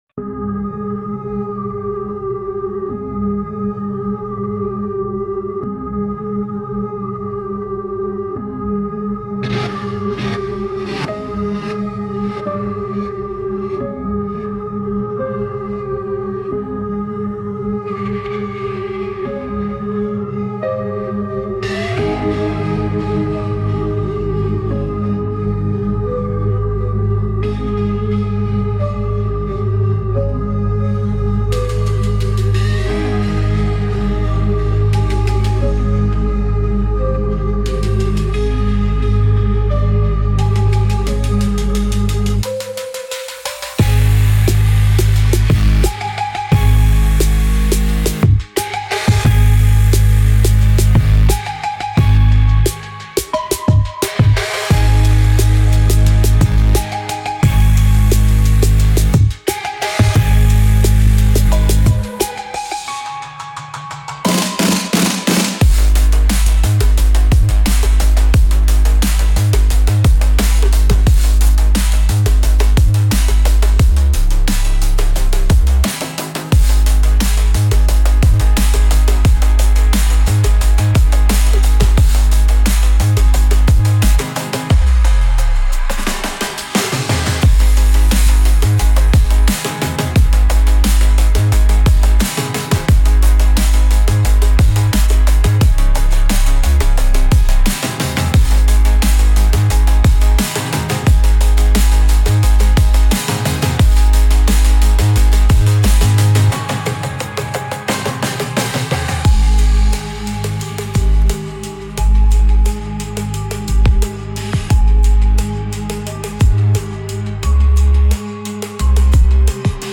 Instrumental - Real Liberty Media Dot XYZ -- 4.00.mp3 - Grimnir Radio